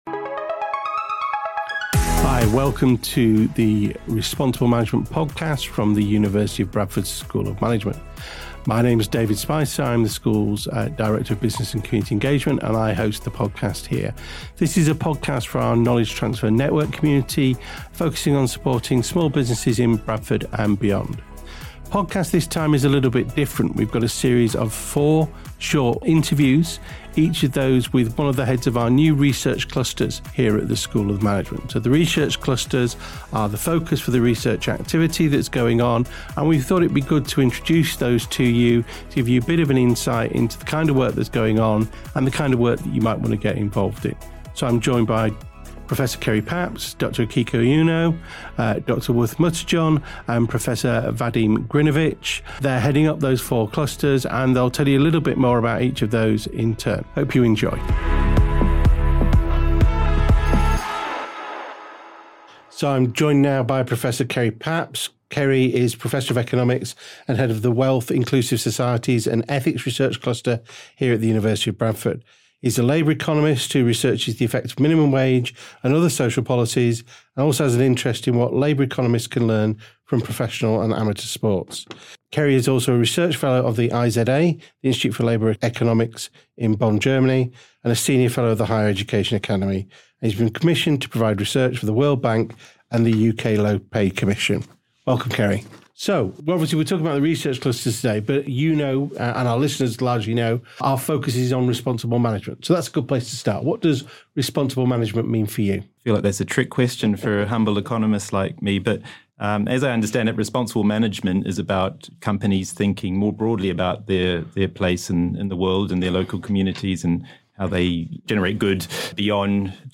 Through four shorter interviews our research cluster leads will provide you insight into the research their members are undertaking, the impa...